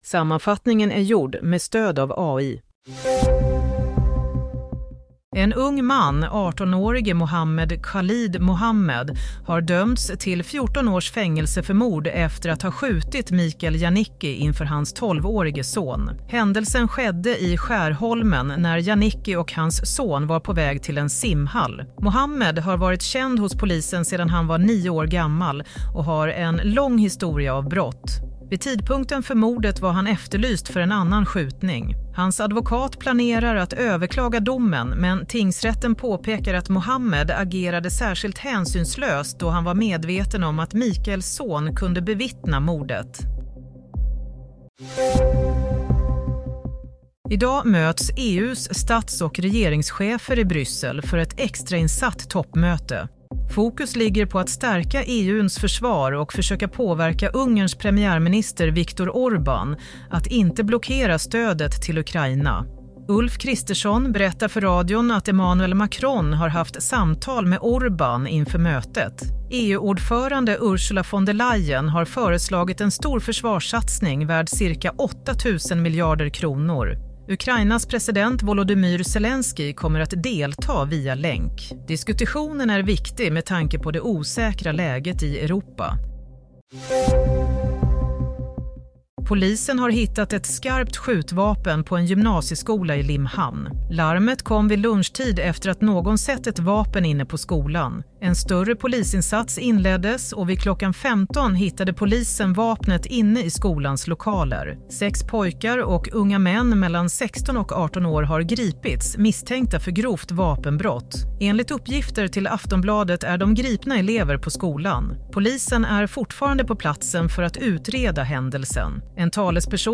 Nyhetssammanfattning – 6 mars 16:00